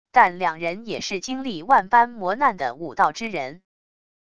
但两人也是经历万般磨难的武道之人wav音频生成系统WAV Audio Player